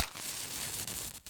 strike.ogg